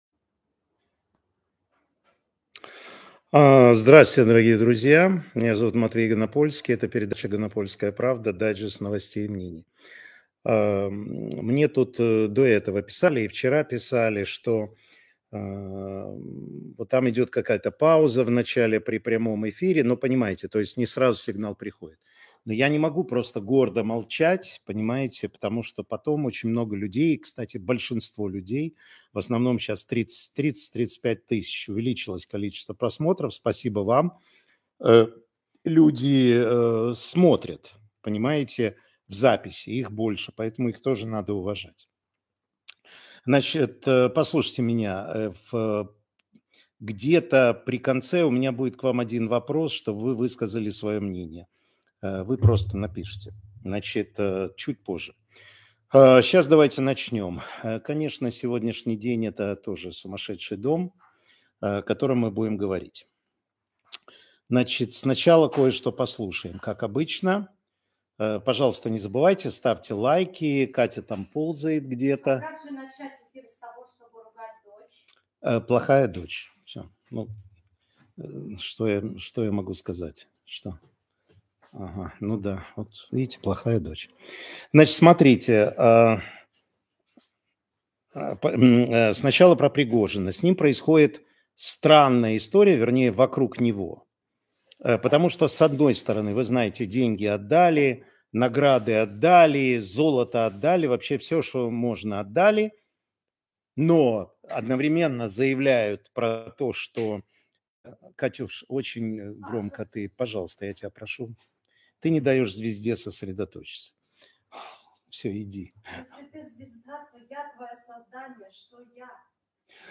Информационно-аналитический эфир Матвея Ганапольского.